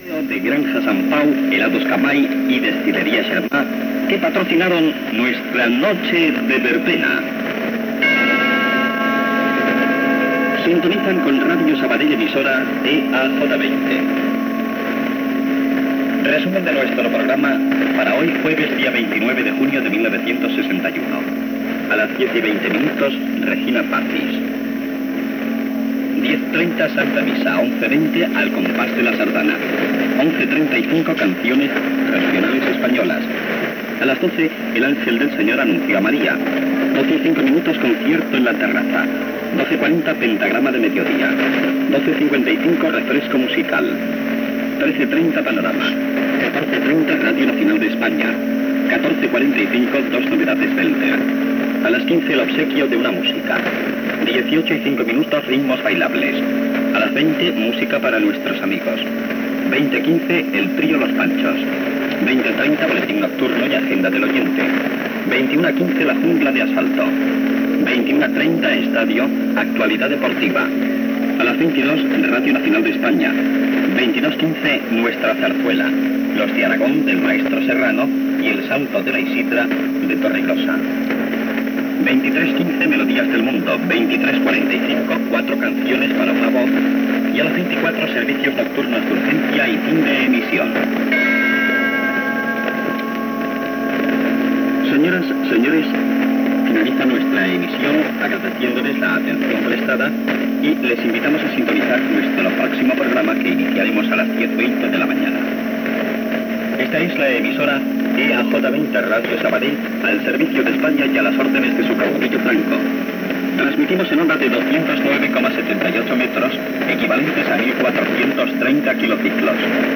Comiat "Noche de verbena", identificació, propera programació, a partir de les 10h i fins les 24h, identificació i tancament de l'emissió.